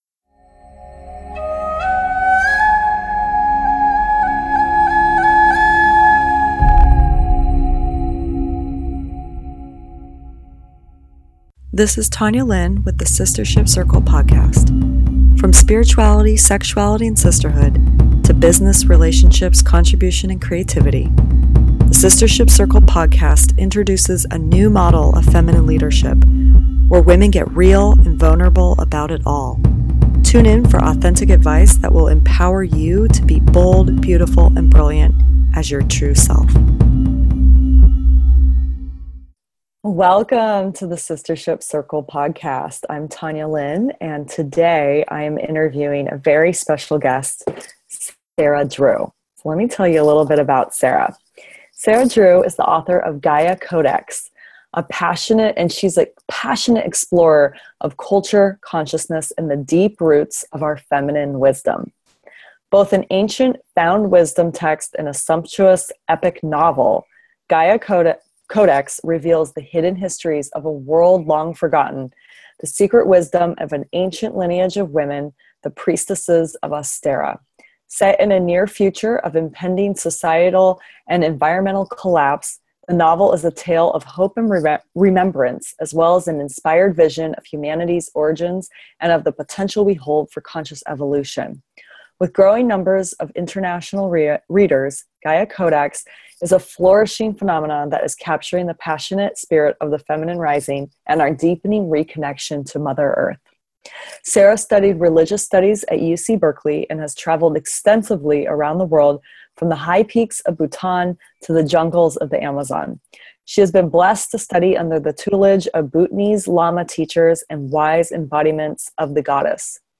9:35 – a reading from the book 12:45 – how to be a feminine leader and do women’s work 13:30 – who we are as women as vessels